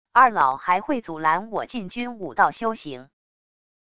二老还会阻拦我进军武道修行wav音频生成系统WAV Audio Player